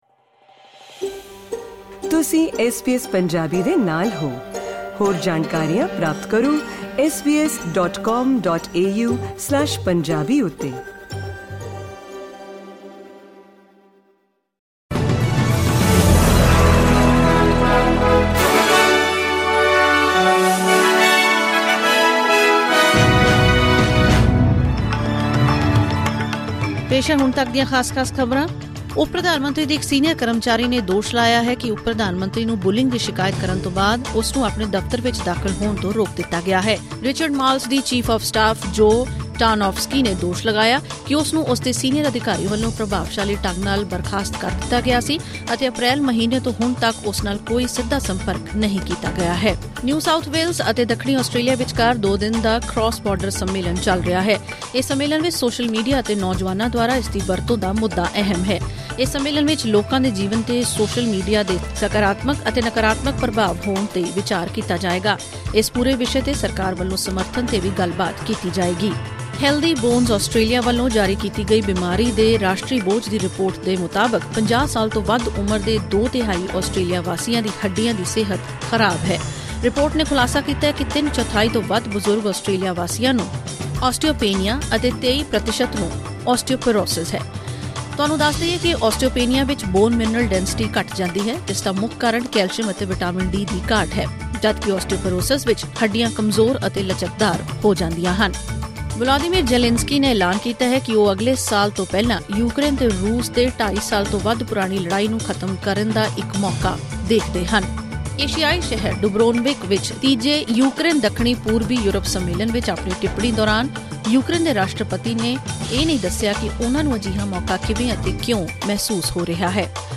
ਐਸ ਬੀ ਐਸ ਪੰਜਾਬੀ ਤੋਂ ਆਸਟ੍ਰੇਲੀਆ ਦੀਆਂ ਮੁੱਖ ਖ਼ਬਰਾਂ: 10 ਅਕਤੂਬਰ 2024